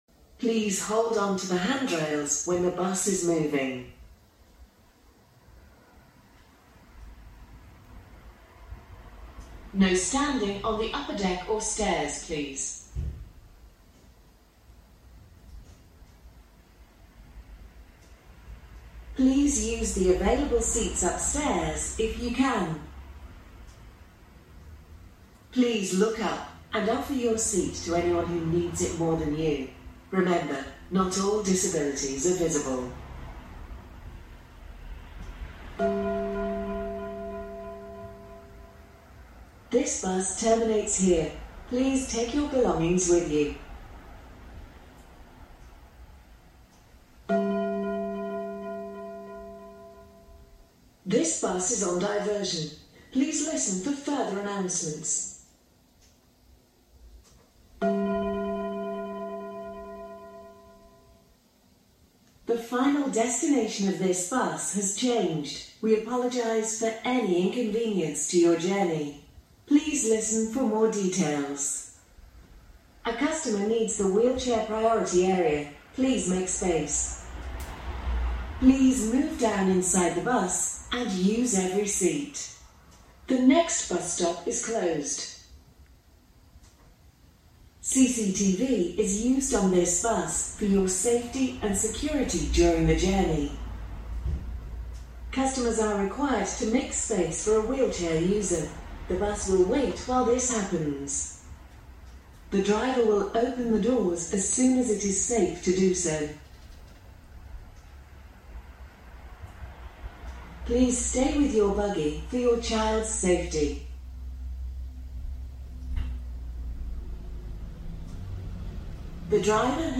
London iBus announcements full list